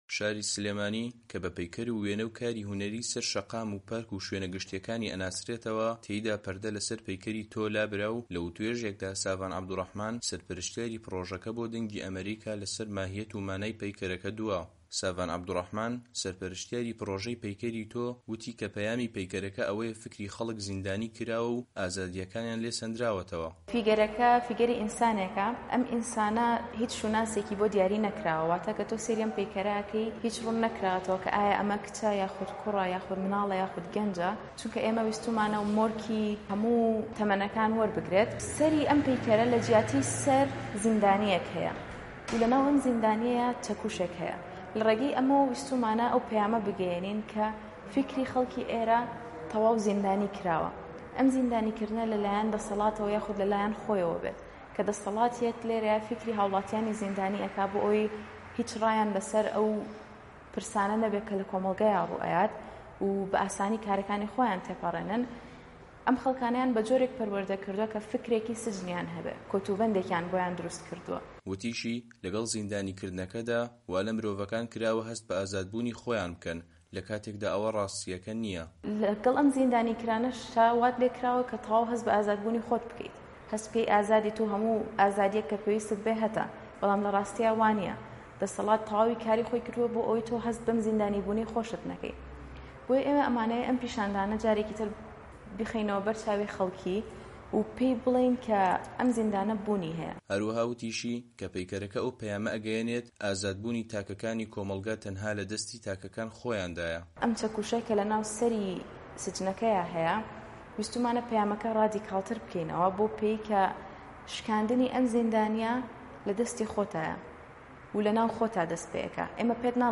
لە مەراسیمێکدا لە شاری سلێمانی پەردە لەسەر پەیکەری تۆ لابرا و یەکێک لە سەرپەرشتیارانی پڕۆژەی پەیکەری تۆ لەبارەی ماهییەت و گرنگی پەیکەرەکە بۆ دەنگی ئەمەریکا دوا.